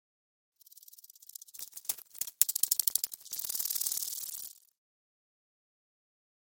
Звуки божьей коровки
Божья коровка мягко села (звук)